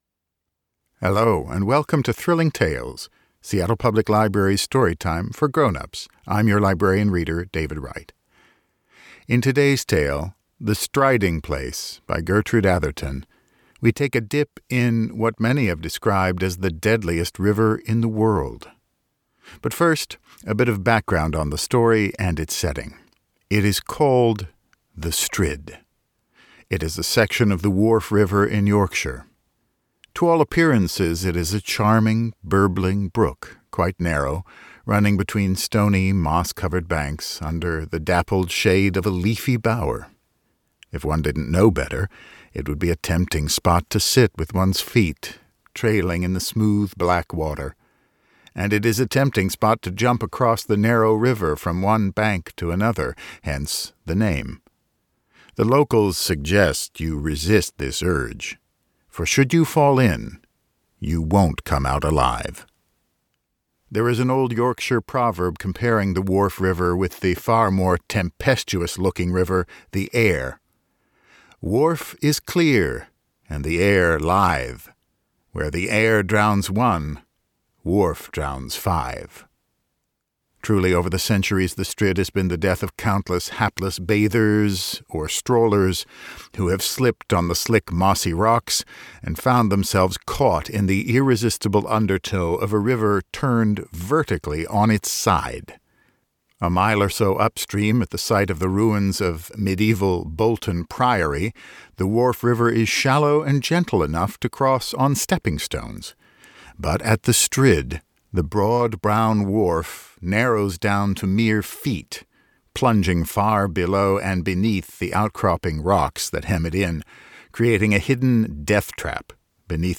The Seattle Public Library is about to get spooky with Virtual Thrilling Tales: A Story Time for Grown-Ups.